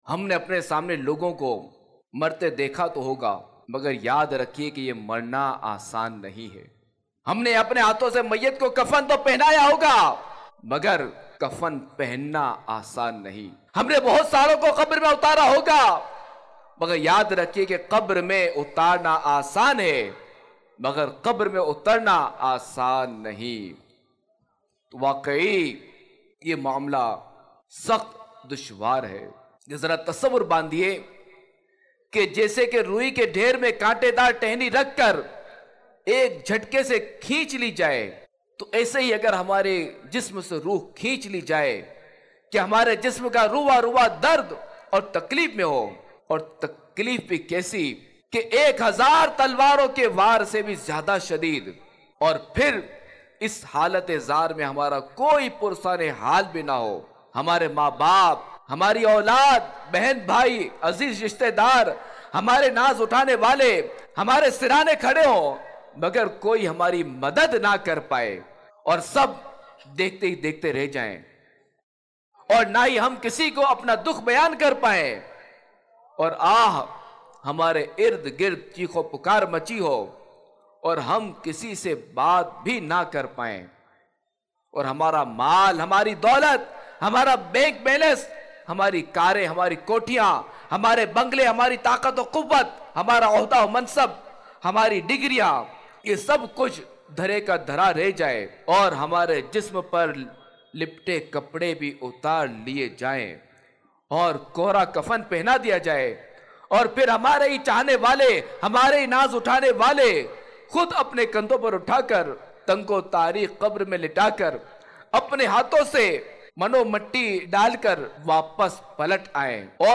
khutba